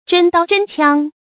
真刀真槍 注音： ㄓㄣ ㄉㄠ ㄓㄣ ㄑㄧㄤ 讀音讀法： 意思解釋： ①真實的刀槍。②比喻毫不作假，實實在在。